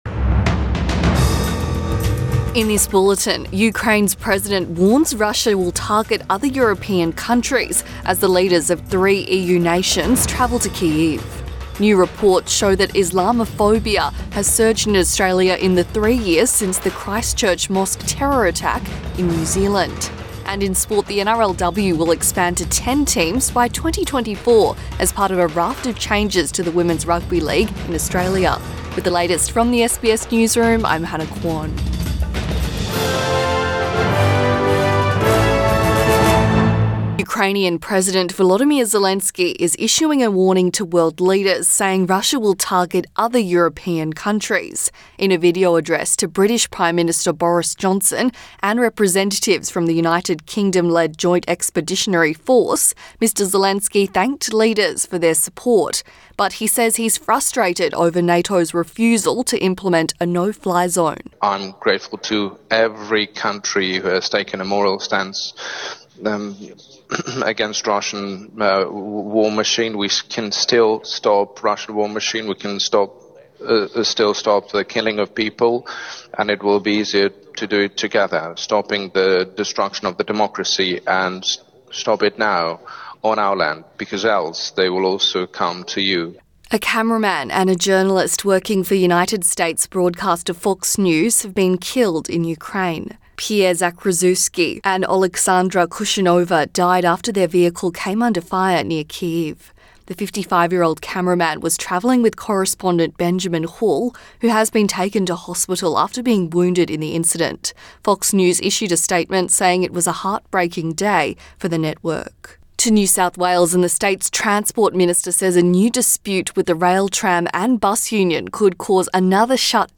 AM bulletin 16 March 2022